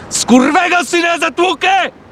Worms speechbanks
INCOMING.wav